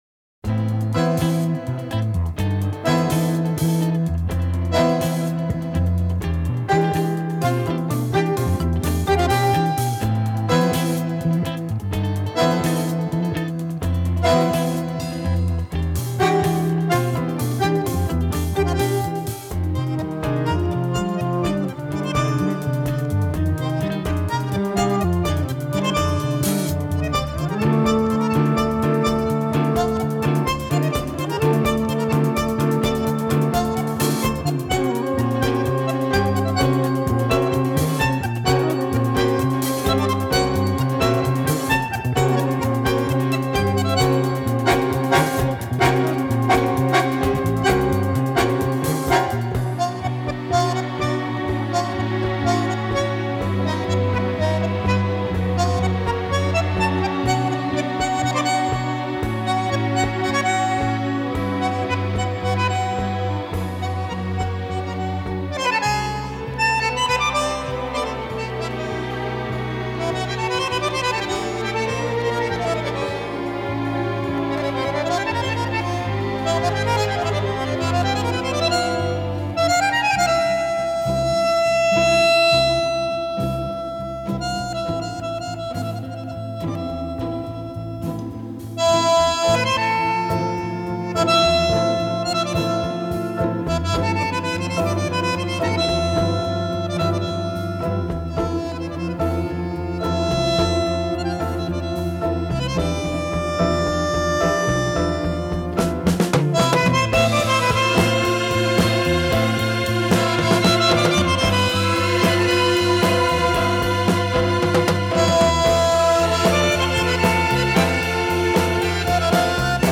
Танго
bandoneon
guitar
violin
viola
cello
piano, organ
marimba
Recorded in Milan, Italyin May 1974